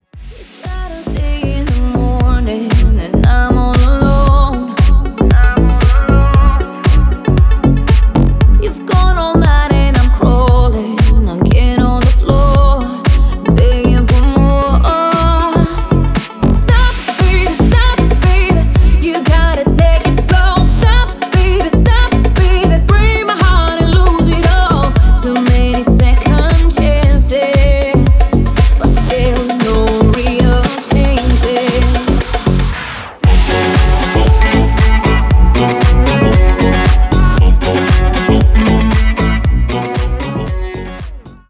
deep house
восточные мотивы
dance
EDM
Club House
красивый женский голос